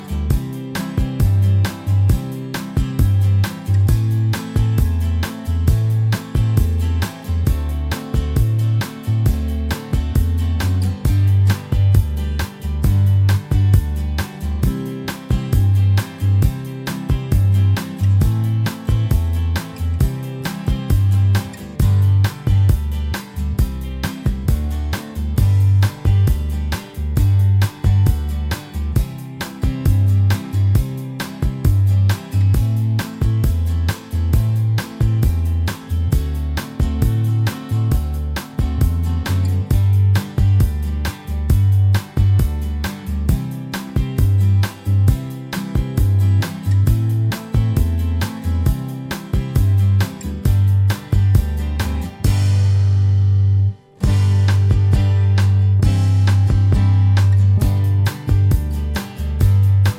Minus Electric Guitar Soft Rock 6:16 Buy £1.50